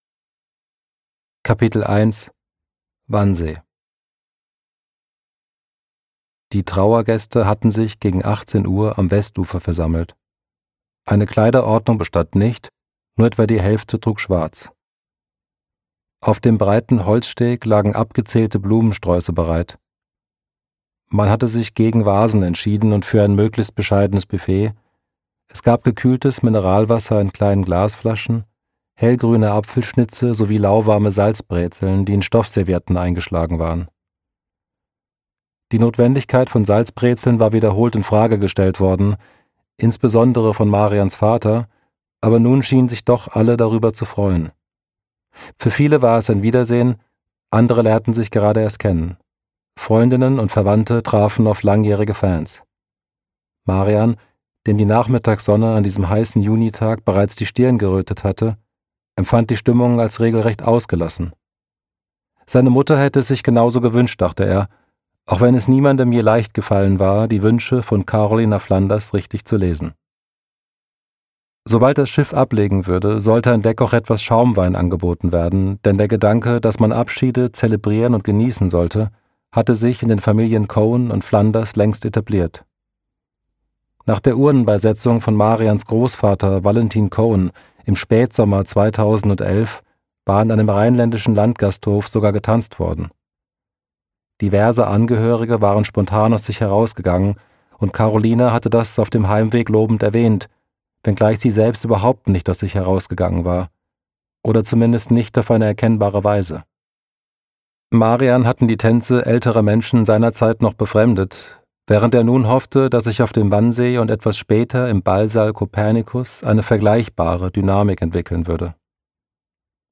Ein optimistisches Hörbuch über traurige Abschiede: Leif Randt erfindet das Coming-of-Middleage.